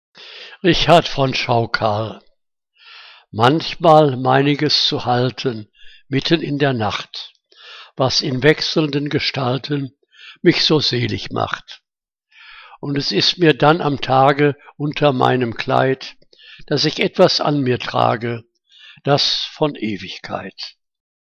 Liebeslyrik deutscher Dichter und Dichterinnen - gesprochen (Richard von Schaukal)